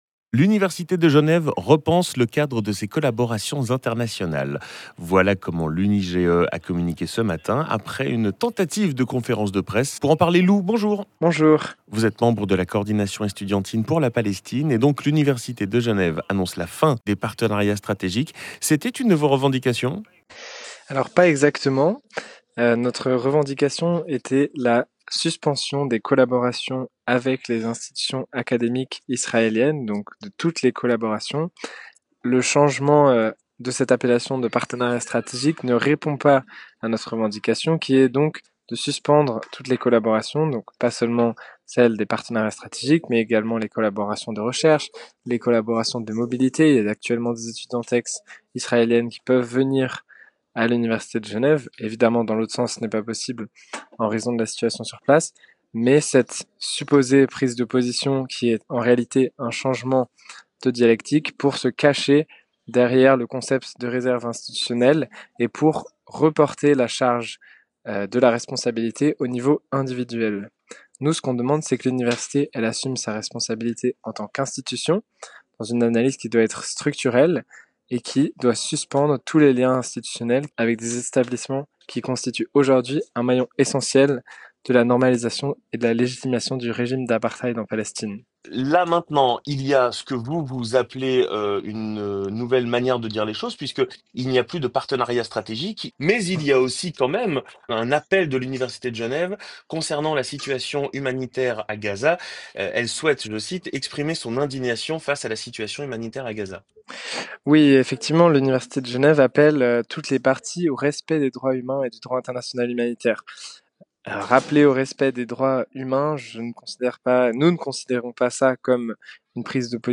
[ITW] Partenariats avec Israël: ces étudiants jugent la position de l’UNIGE insuffisante